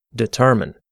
/dɪˈtɜːʳmɪn/), but you don’t know that, so you say /ˈdiːtərmaɪn/ again and again, until it becomes natural for you.
determine.mp3